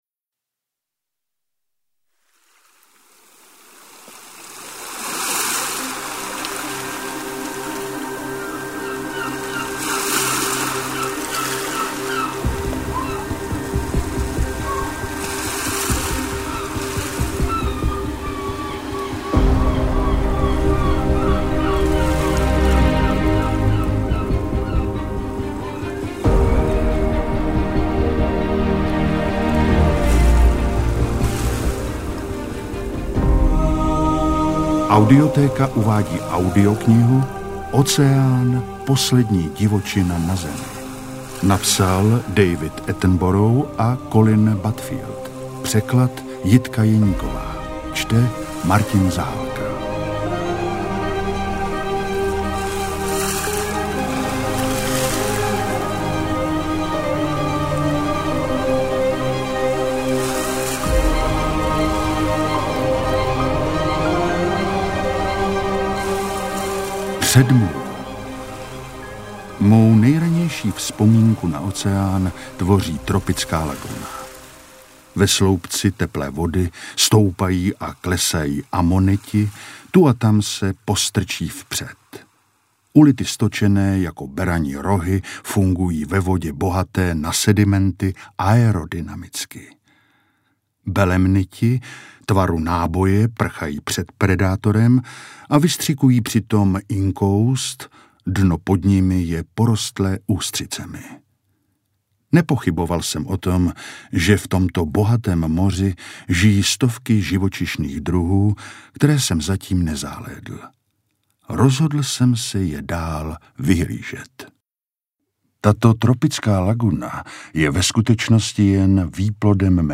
MP3 Audiobook